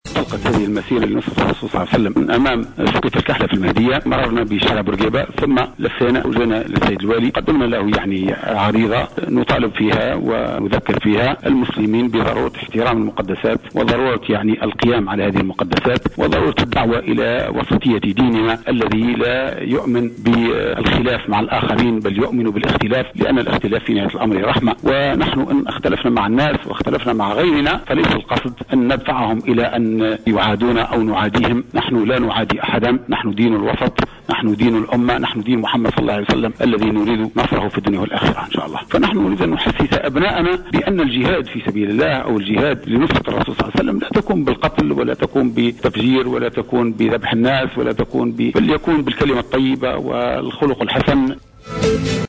أحد المشاركين في المسيرة